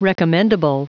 Prononciation du mot recommendable en anglais (fichier audio)
Prononciation du mot : recommendable